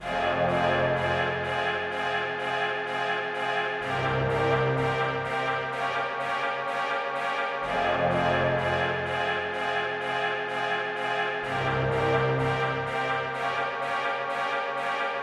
描述：一个传统的Boduberu节拍。这种风格通常由三面鼓演奏，这面鼓作为主拍，其他两面鼓演奏变化。
标签： 126 bpm Ethnic Loops Percussion Loops 497.79 KB wav Key : Unknown
声道立体声